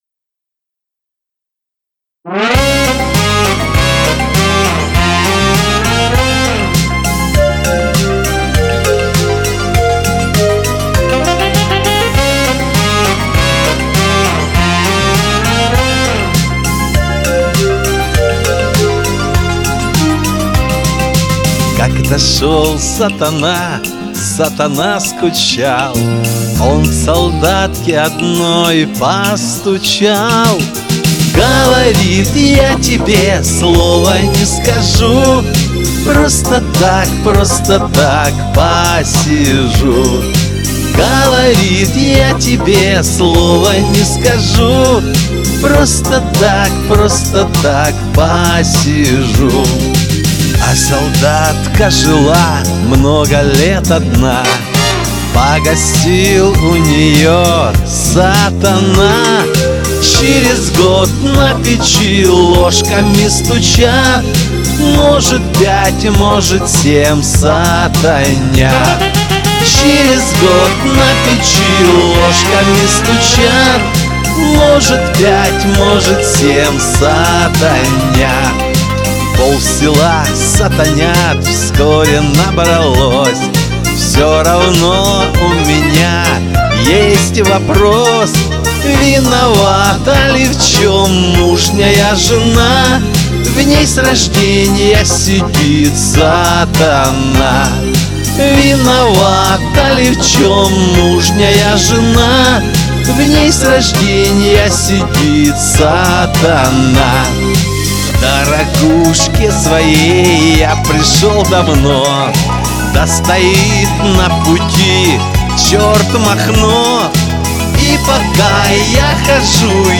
Более естественное исполнение!